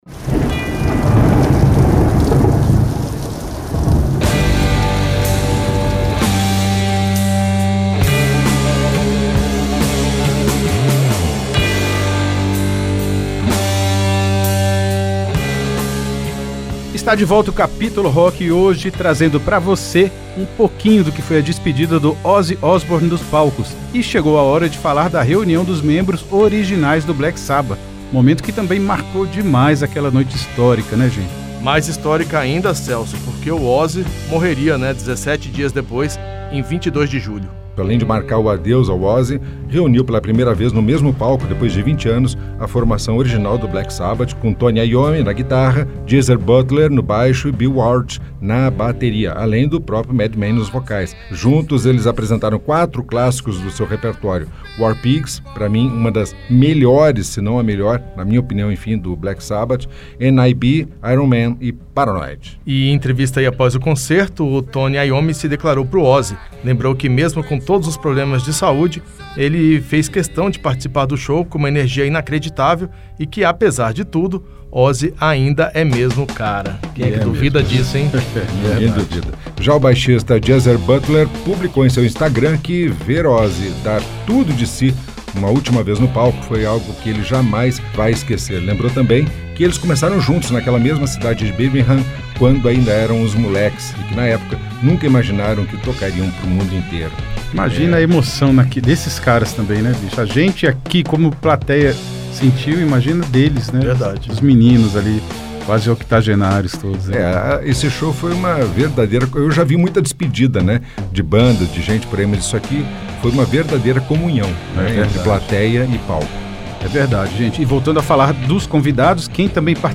O programa relembra os momentos mais marcantes desse adeus, com muita música para celebrar o legado do pai do heavy metal.